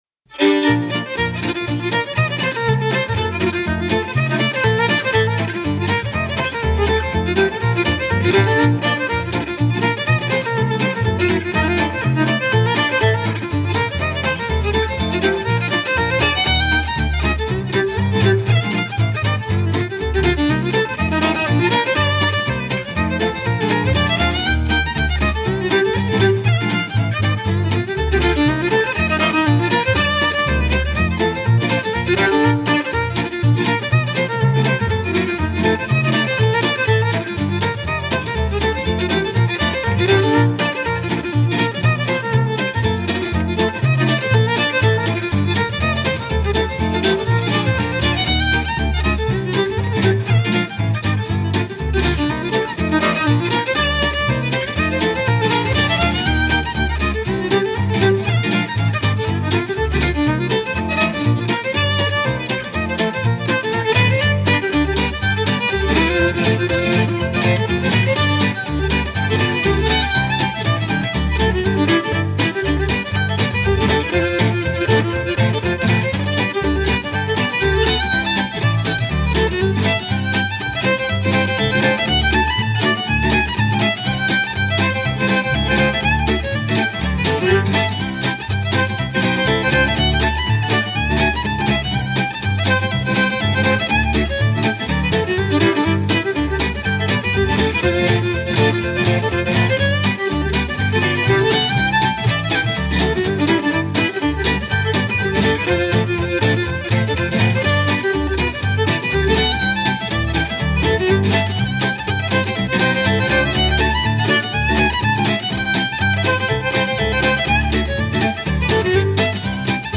fluid style
floating European swing